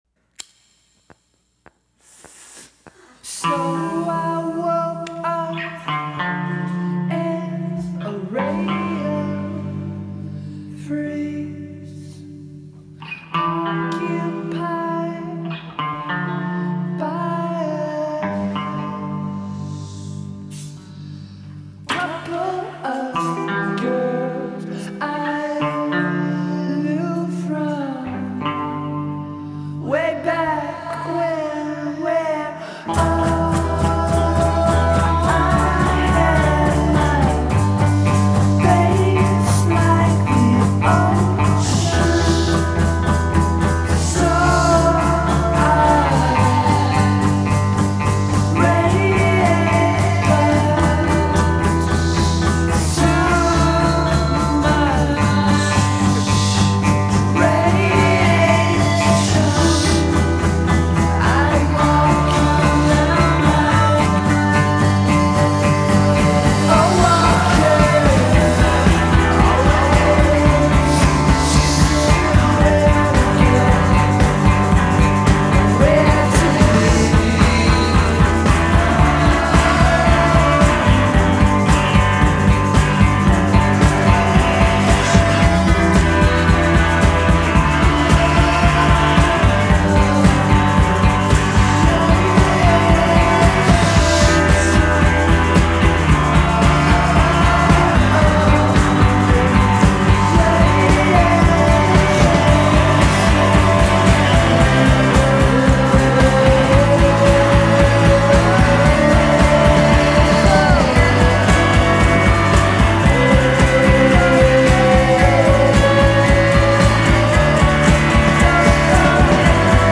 It’s beauty with a blade hidden within its folds.